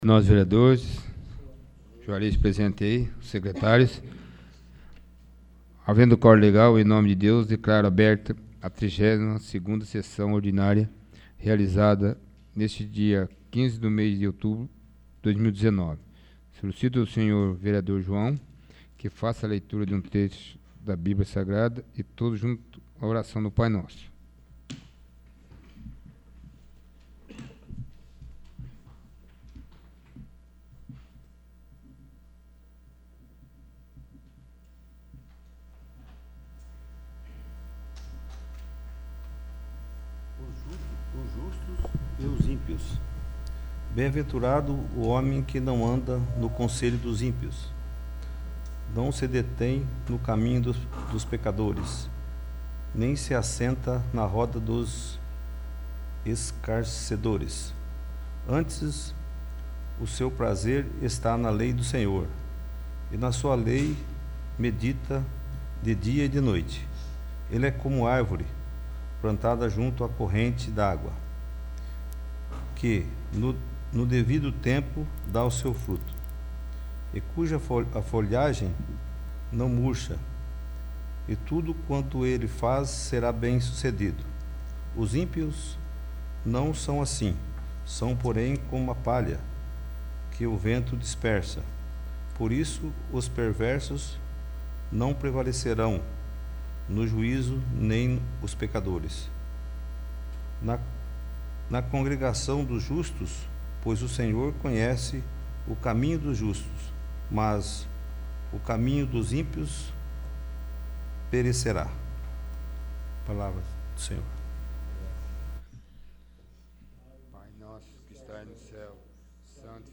32º. Sessão Ordinária